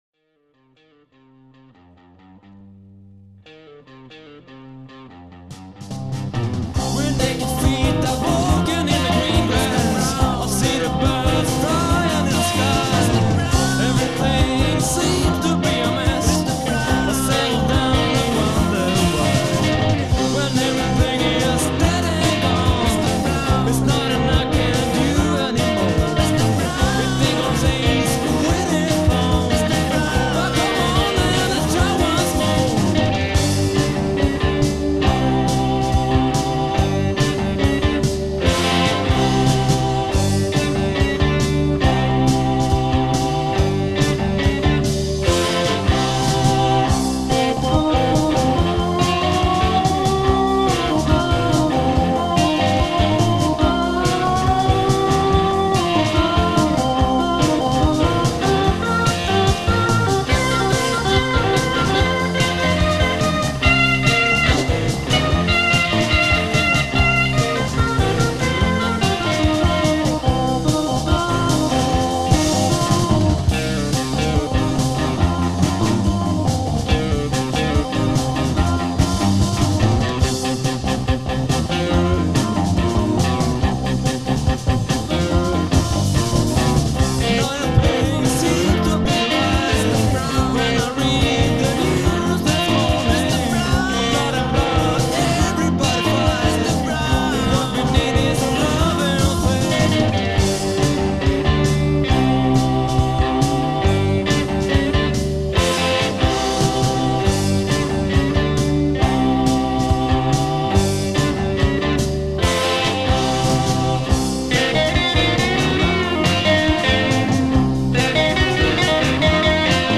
March 13-14, 1982 (From pure old vinyl record)